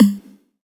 SNARE 44  -R.wav